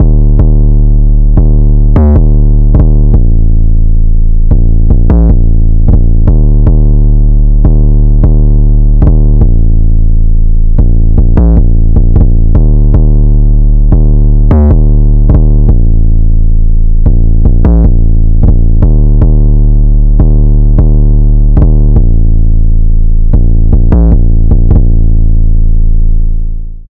SLIDE UP 808.wav